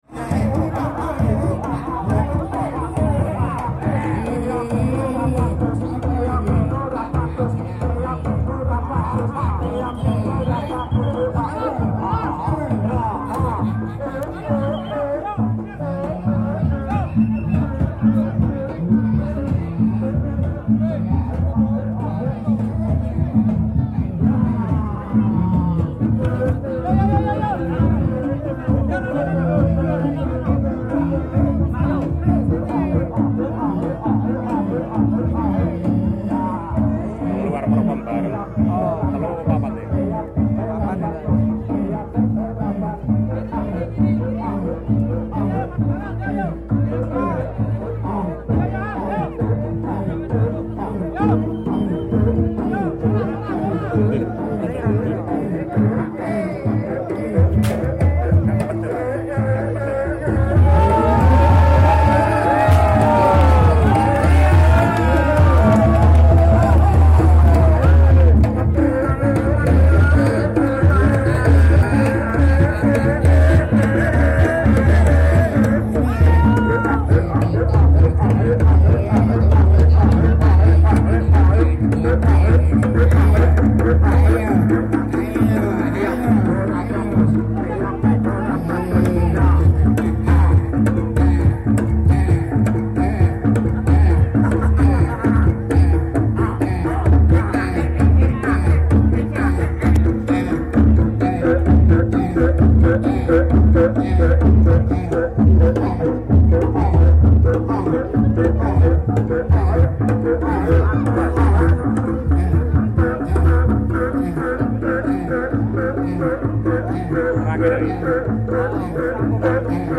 Penampilan 5 Seni Reog Ponorogo dari PBS memukau penonton saat Karnaval Budaya Desa Mandong 2025.